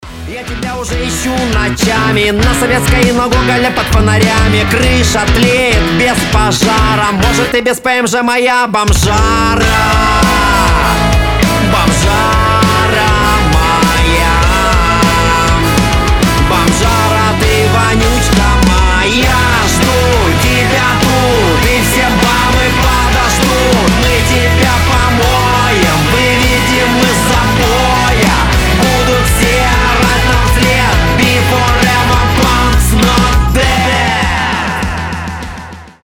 • Качество: 320, Stereo
смешные
панк-рок